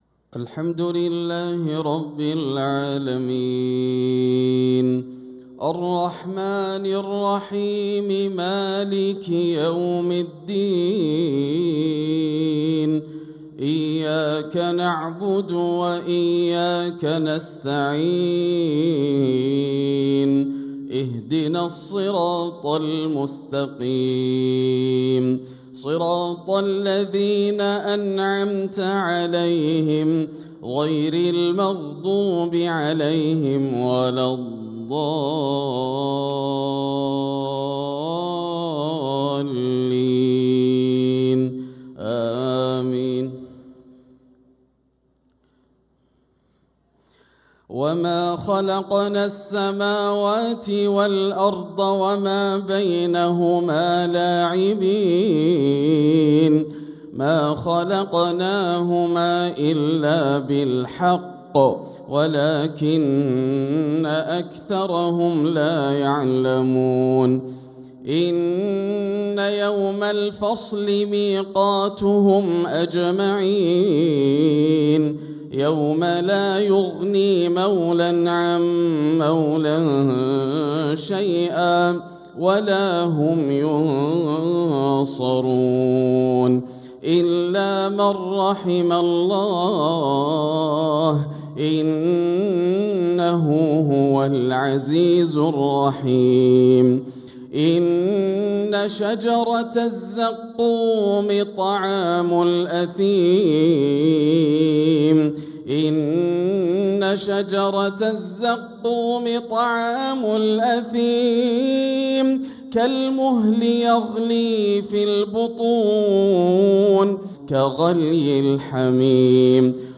صلاة المغرب للقارئ ياسر الدوسري 4 ربيع الأول 1446 هـ
تِلَاوَات الْحَرَمَيْن .